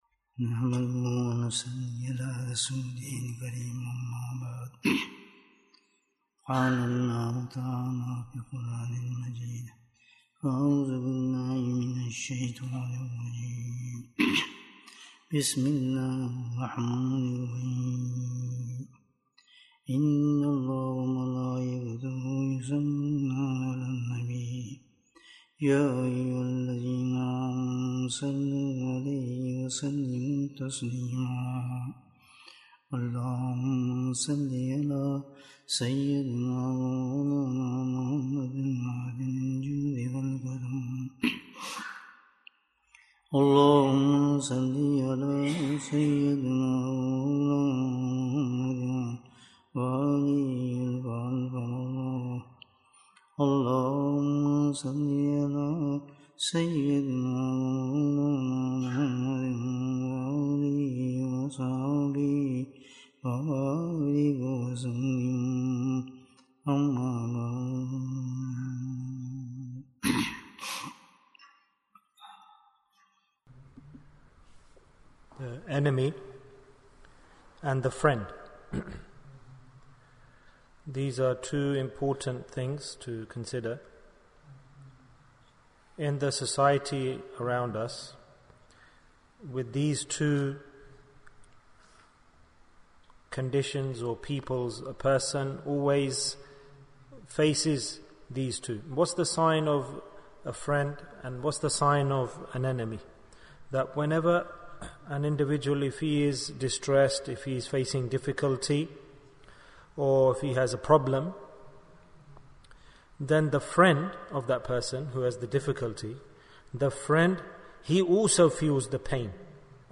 What is Shaytan's Chutney? Bayan, 46 minutes14th July, 2022